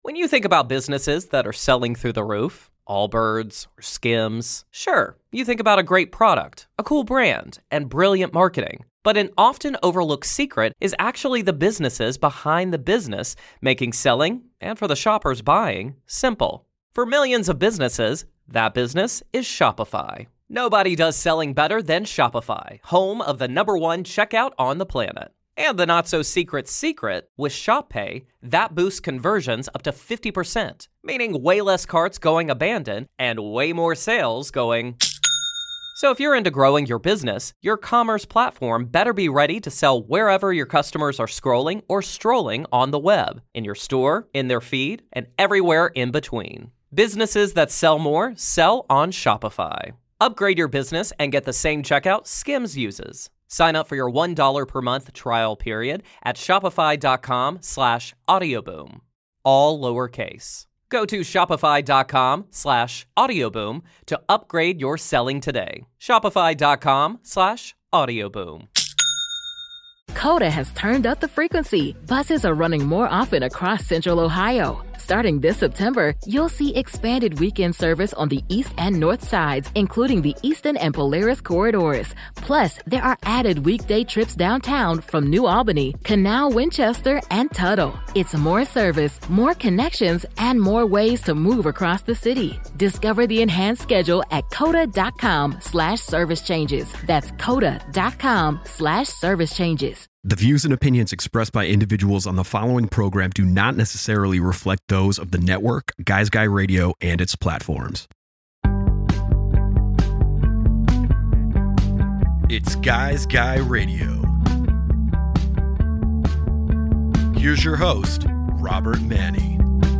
GUY’S GUY RADIO features interviews and in-depth conversations with thought leaders across the worlds of relationships and modern masculinity, spirituality, health, wellness and diet, business, and much more.